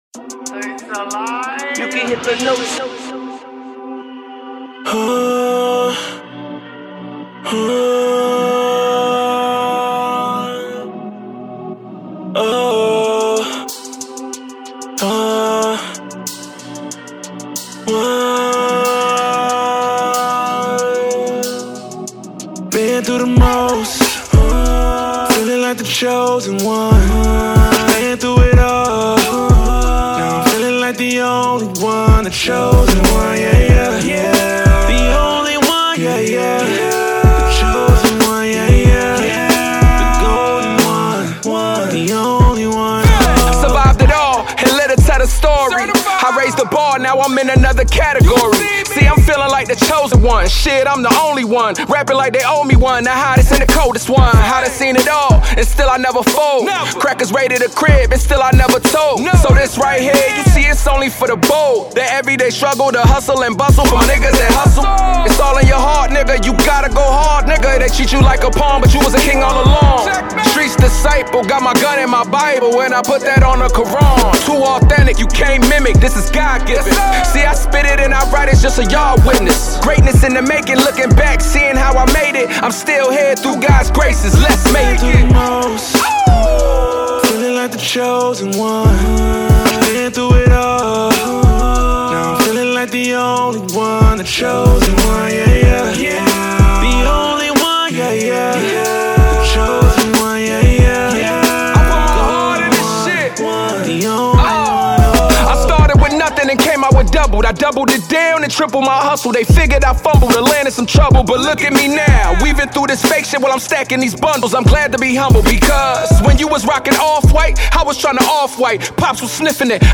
Hiphop
theatrical and cinematic delivery